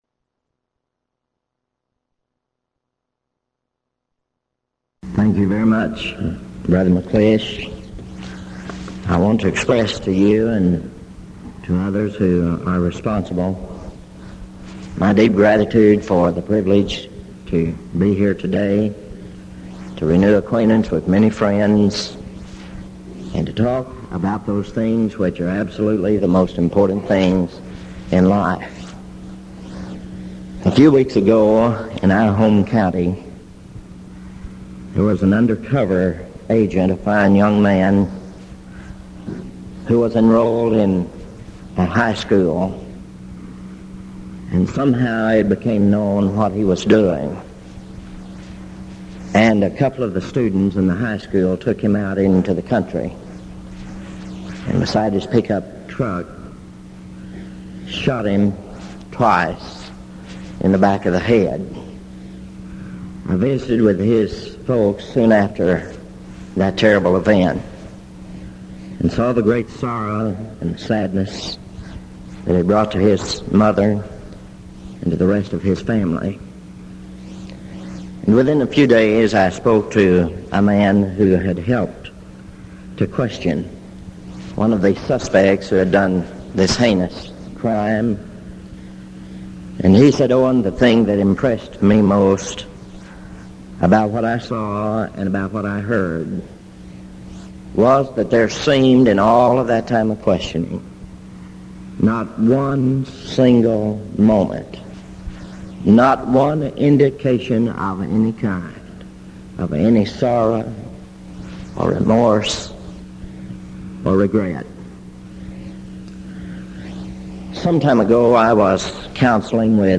Event: 1987 Denton Lectures
lecture